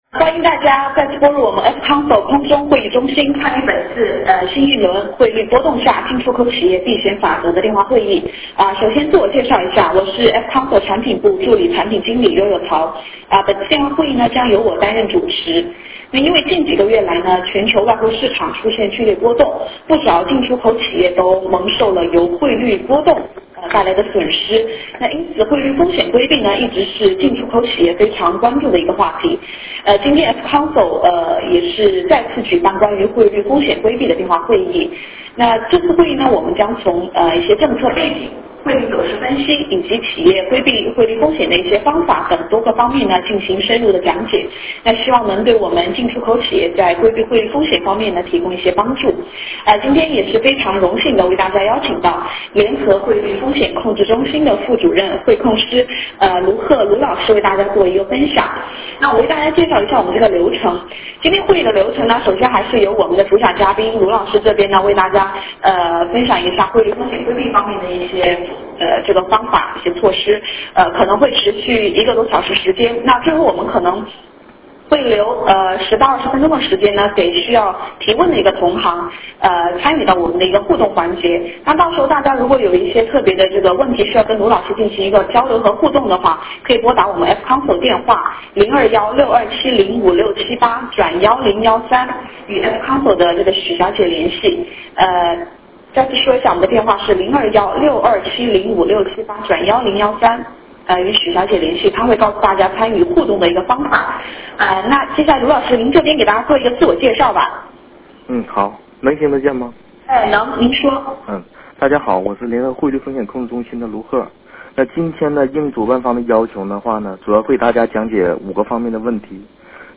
电话会议
Q&A 环节